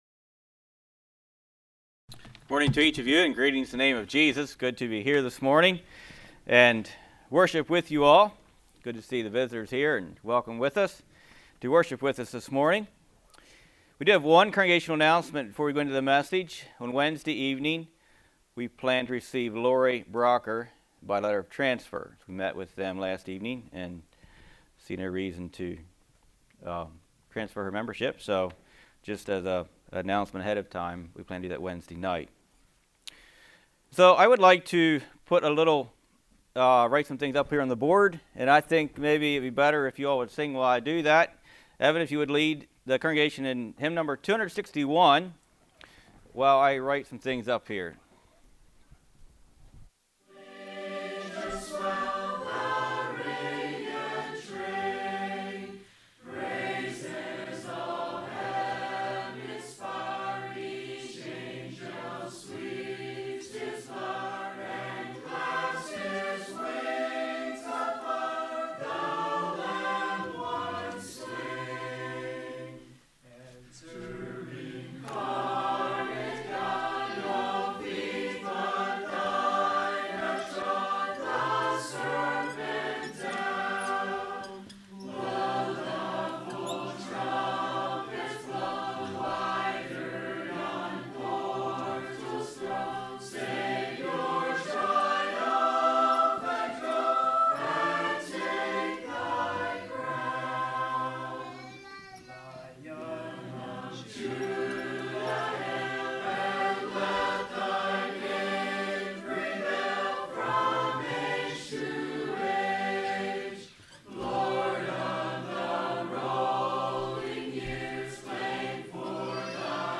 Play Now Download to Device The Doctrine of the Resurrection Congregation: Winchester Speaker